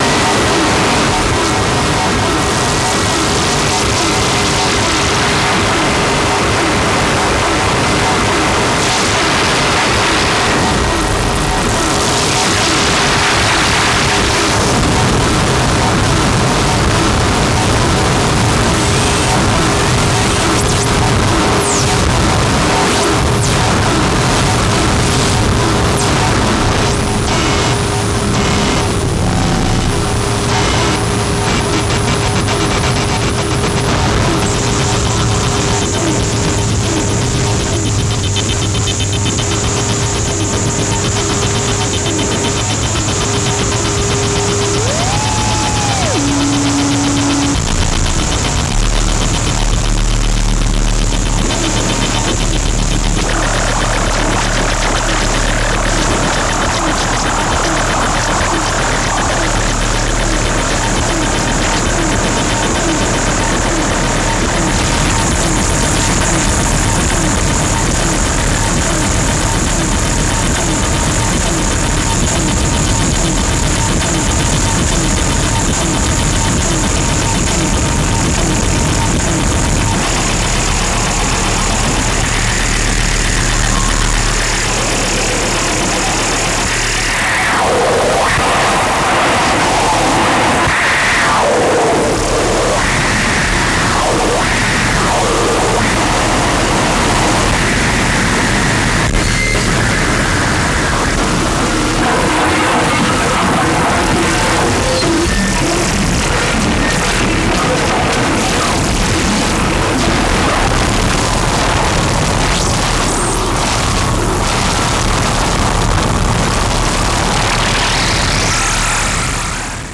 • Genre: Japanese Noise